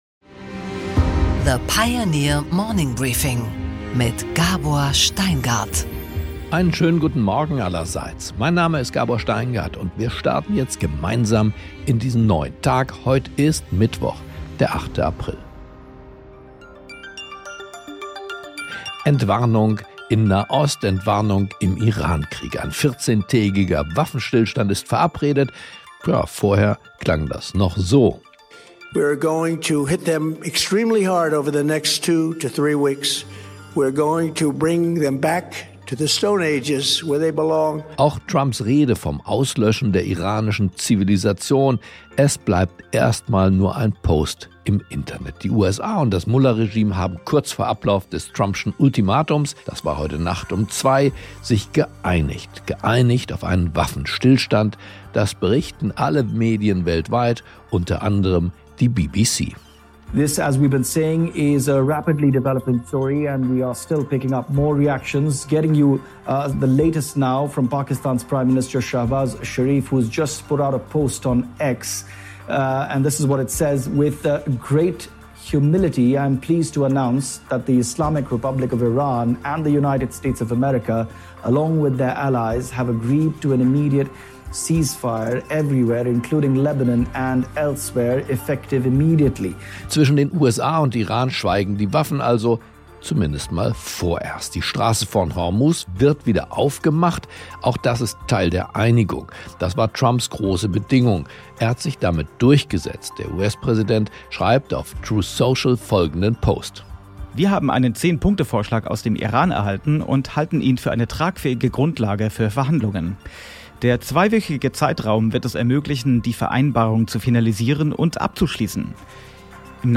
Gabor Steingart präsentiert das Morning Briefing.